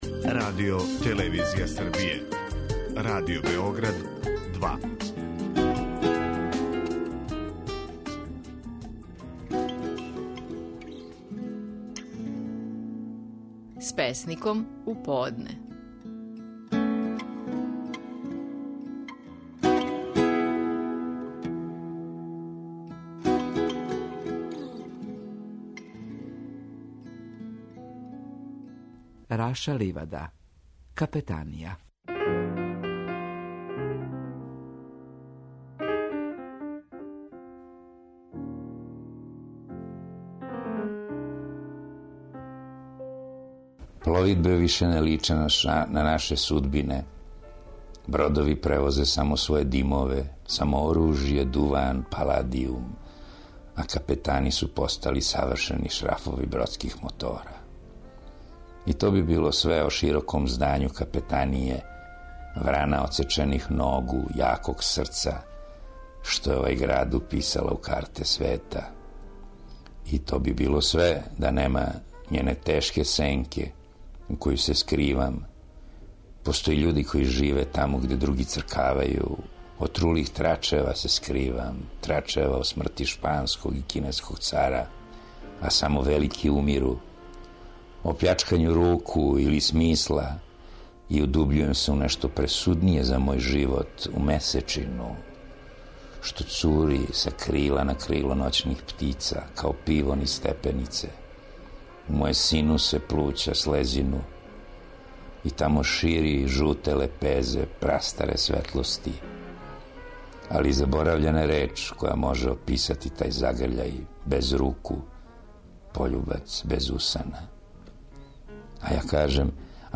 Стихови наших најпознатијих песника, у интерпретацији аутора.
Раша Ливада говори своју песму "Капетанија".